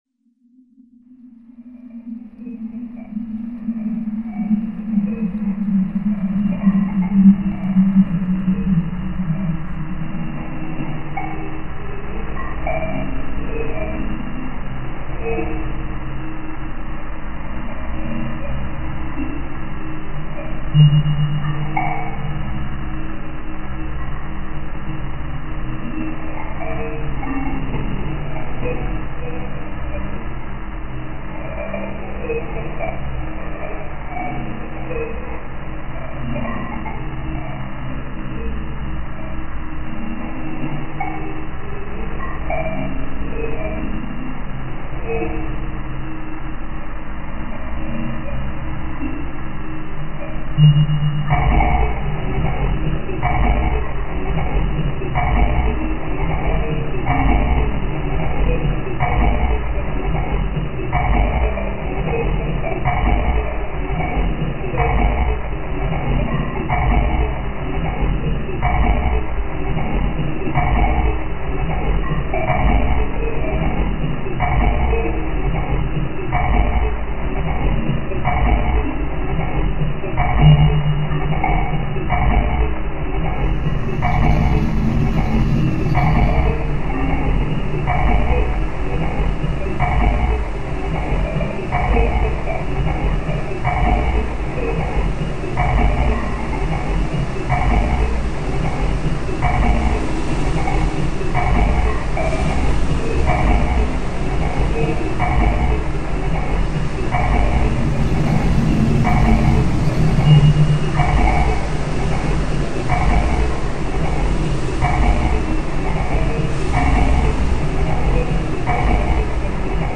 File under: Dark Ambient